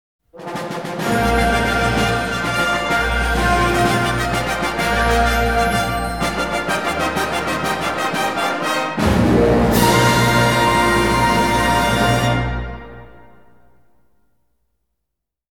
Фанфары